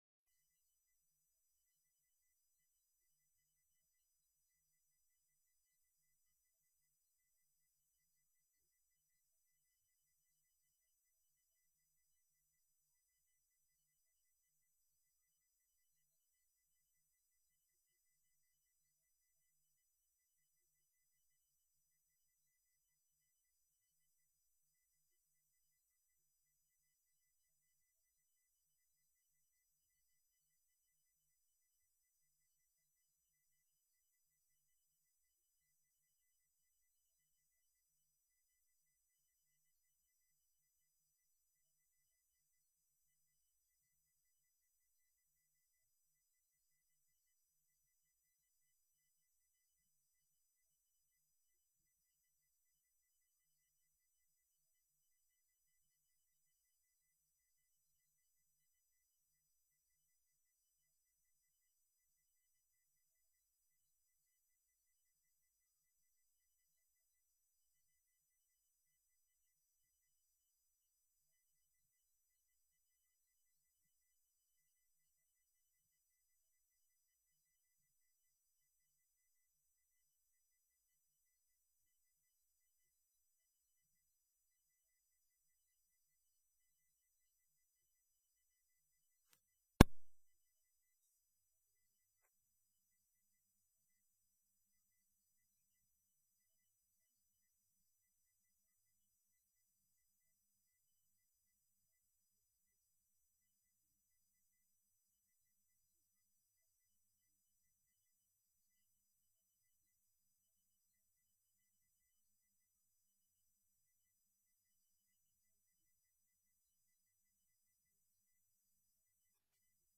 Inspraakbijeenkomst 01 februari 2018 19:30:00, Gemeente Roosendaal